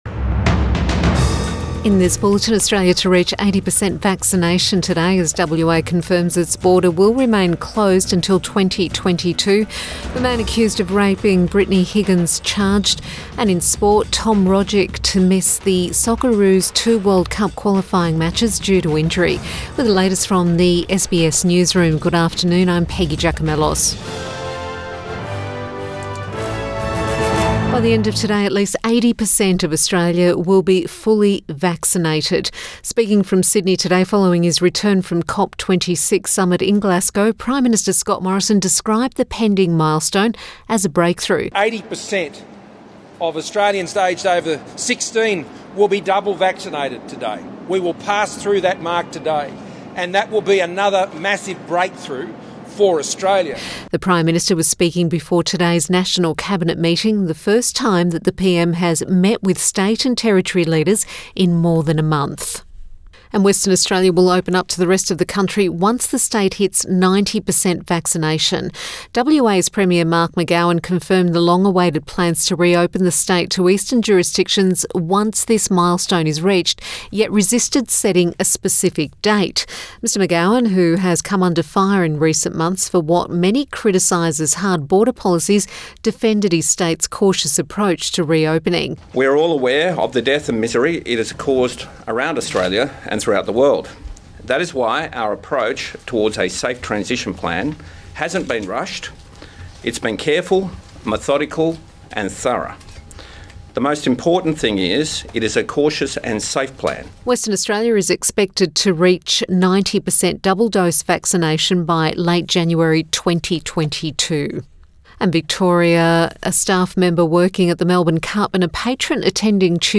PM bulletin November 5 2021